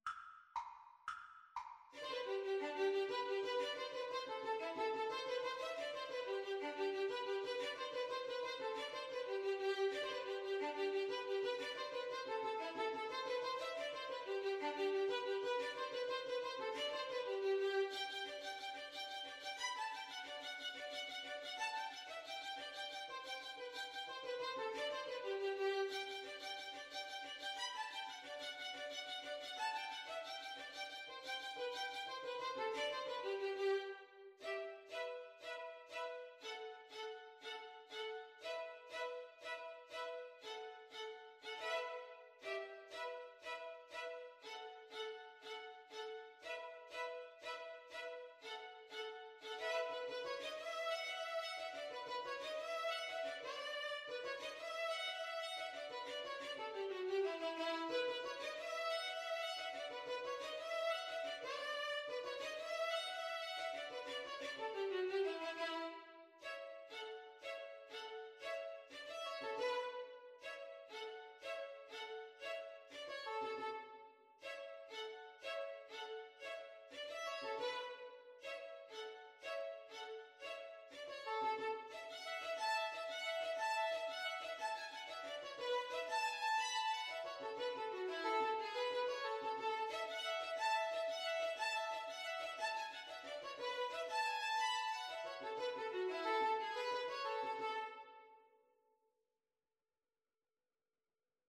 Moderato . = 120
6/8 (View more 6/8 Music)